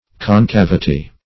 Concavity \Con*cav"i*ty\, n.; pl.